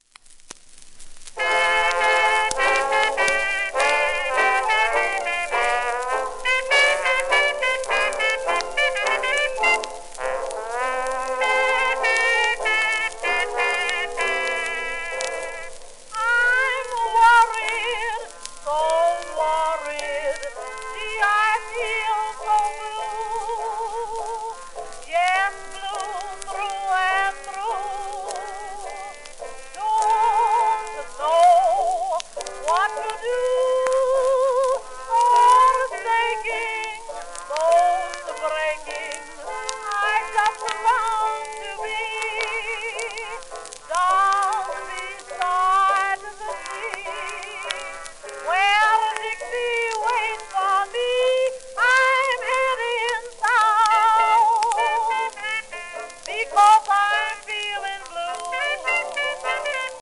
盤質B *面擦れ、キズ
旧 旧吹込みの略、電気録音以前の機械式録音盤（ラッパ吹込み）